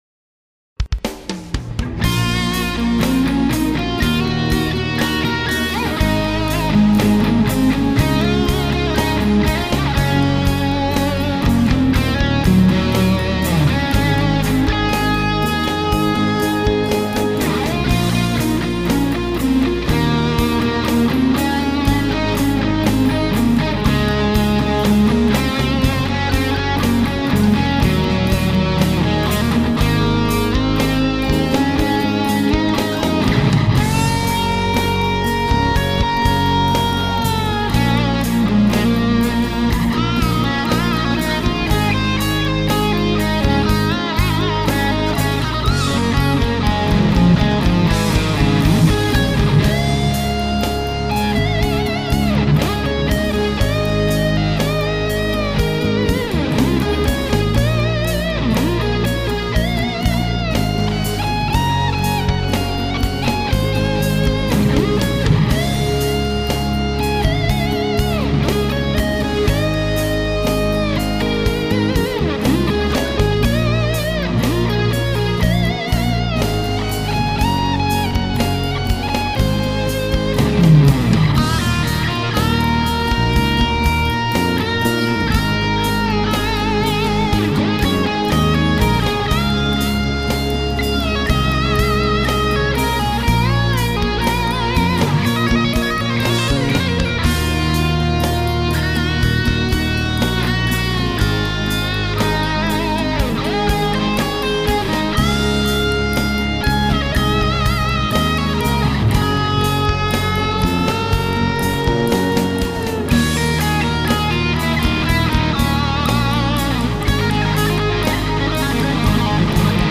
演奏视听：
谱内音轨：独奏电吉他